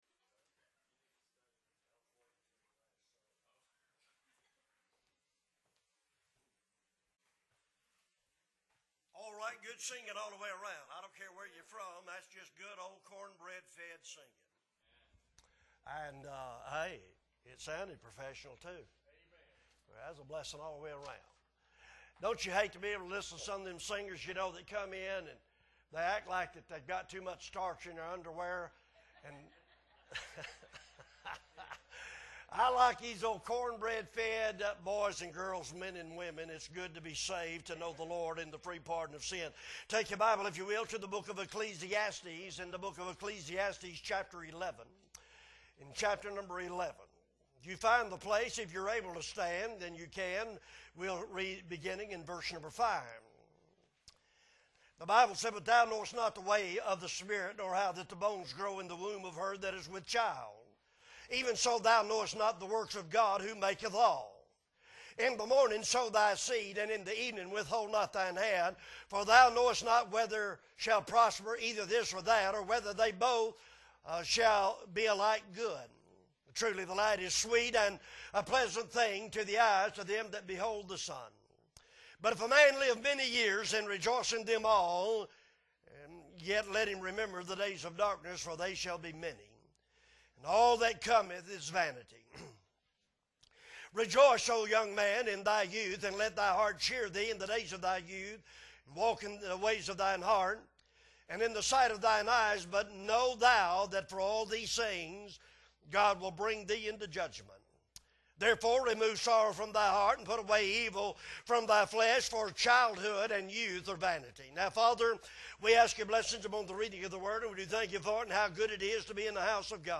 May 28, 2023 Morning Service - Appleby Baptist Church